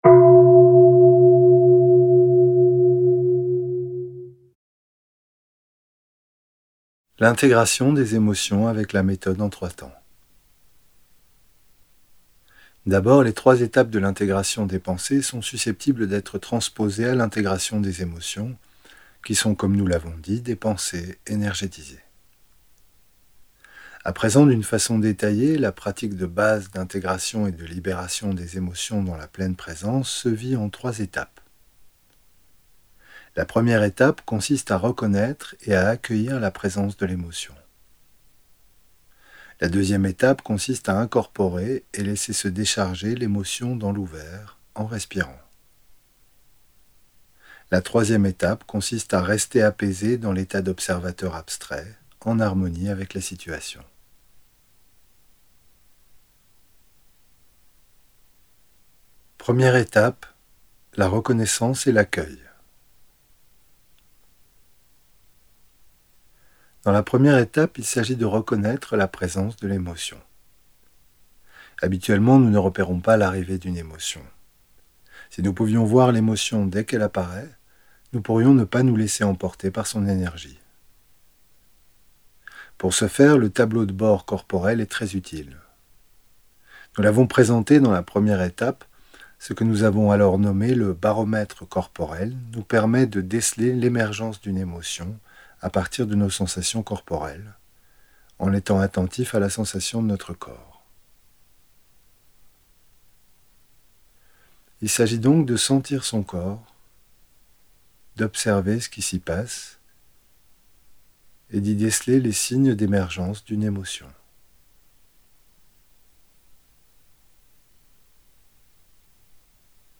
Audio femme